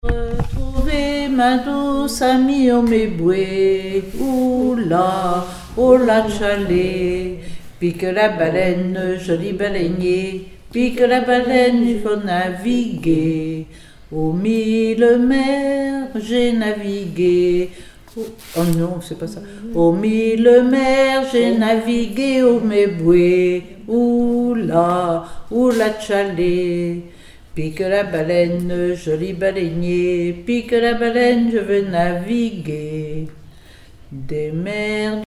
Mémoires et Patrimoines vivants - RaddO est une base de données d'archives iconographiques et sonores.
Chansons et formulettes enfantines
Pièce musicale inédite